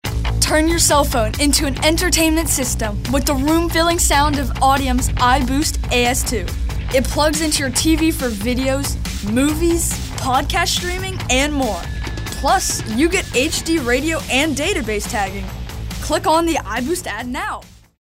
animated, confident, cool, kid-next-door, teenager, young, younger